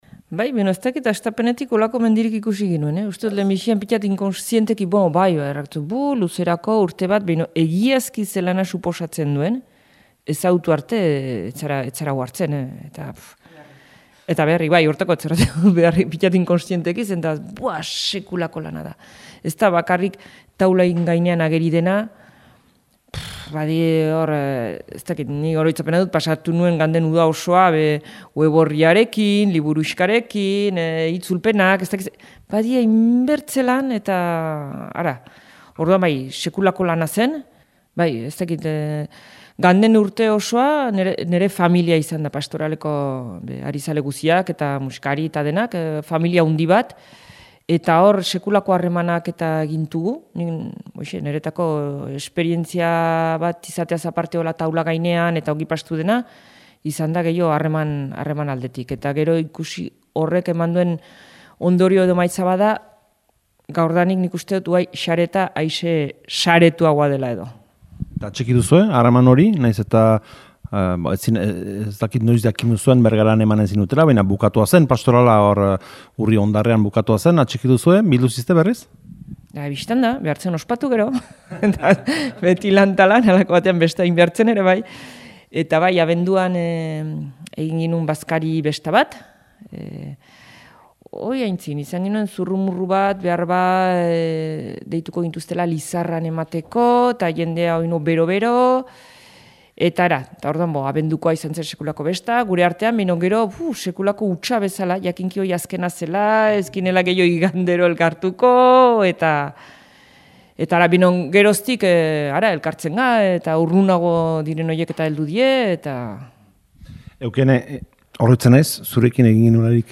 parte hartzaileekin mintzatu gara.